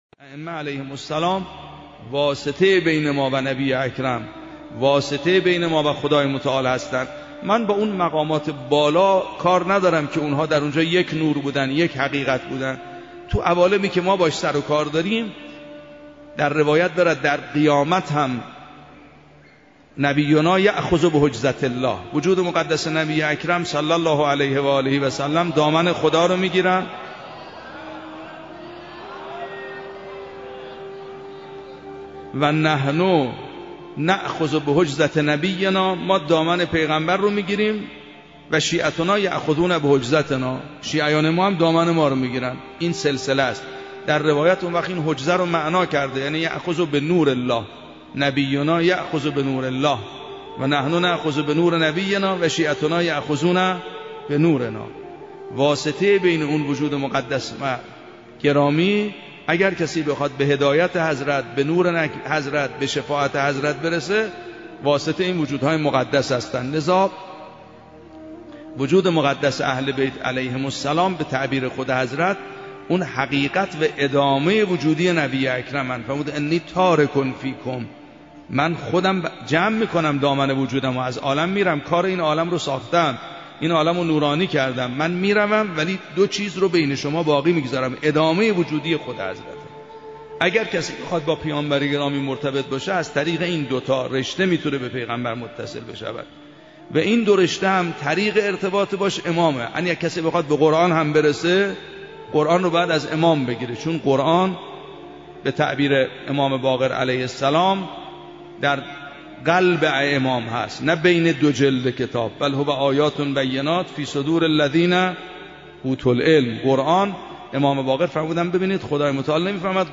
فرازی از سخنان آیت الله سید محمدمهدی میرباقری